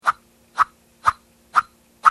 「サク、サク・・・」残念ながら、鳴り砂らしき音は響いてこなかった。
鳴り砂の音が聴けます。